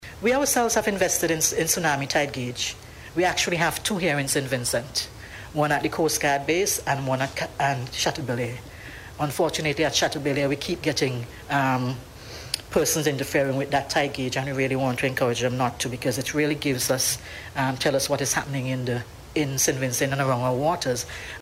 Miss Forbes raised the issue at the opening of the National After Action Review session yesterday.